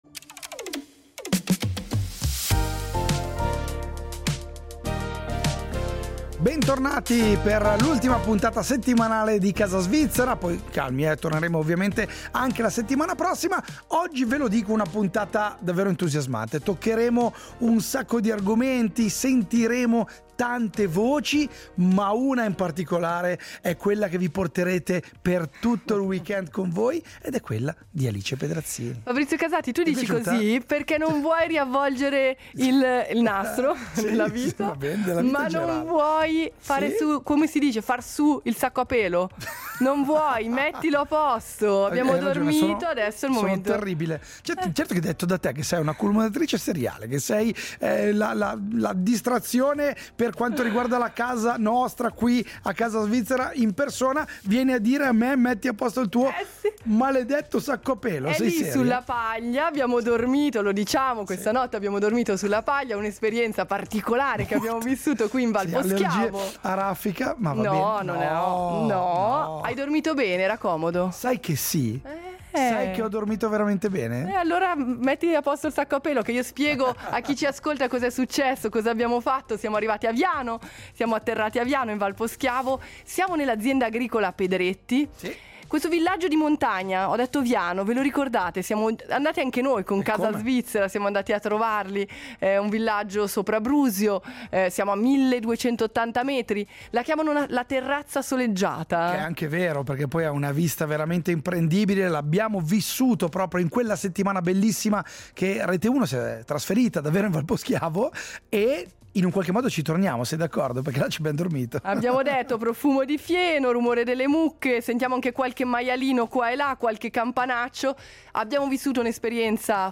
Oggi Casa Svizzera si è svegliata… in stalla.